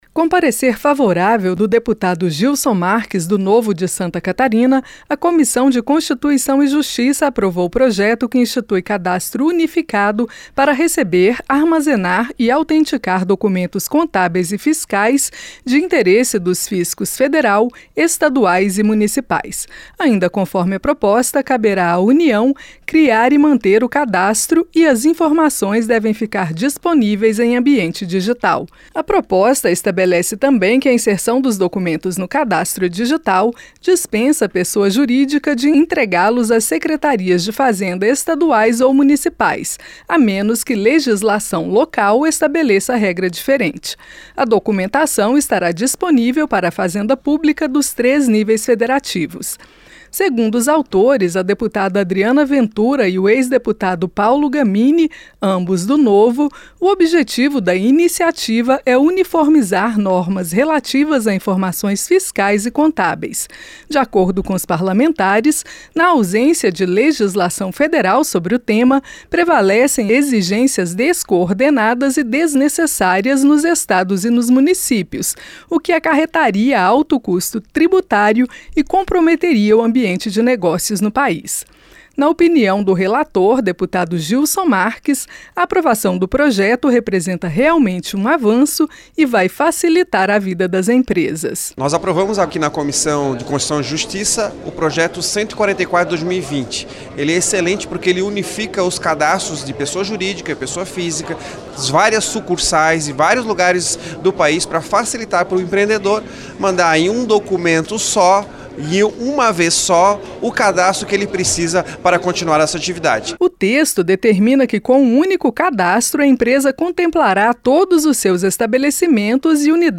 A COMISSÃO DE CONSTITUIÇÃO E JUSTIÇA DA CÂMARA APROVOU PROJETO QUE INSTITUI UM CADASTRO FISCAL UNIFICADO DA UNIÃO, ESTADOS E MUNICÍPIOS. ACOMPANHE NA REPORTAGEM